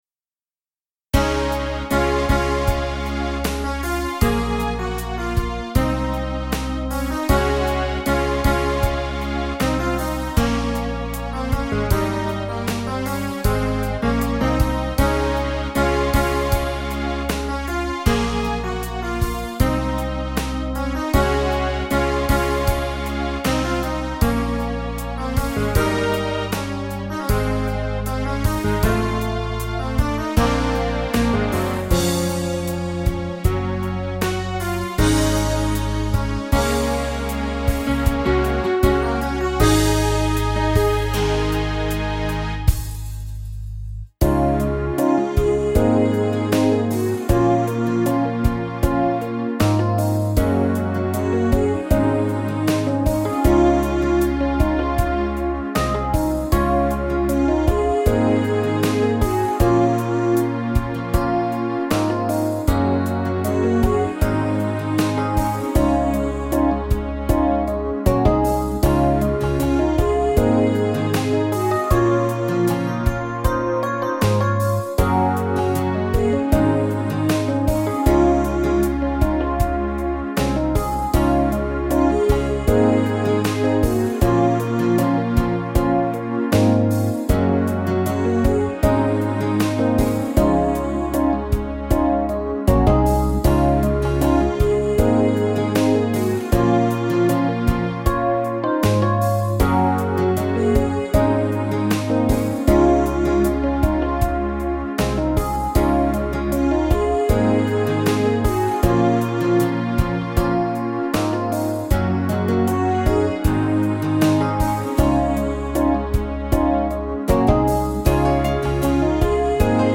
Fonograma
Lietuva Mašanausko ,fonograma.mp3